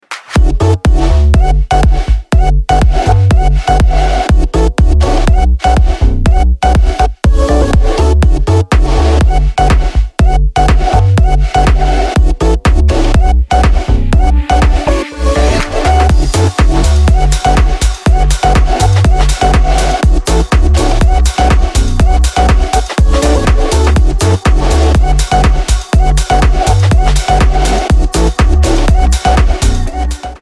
• Качество: 320, Stereo
ритмичные
Electronic
без слов
Стиль: future house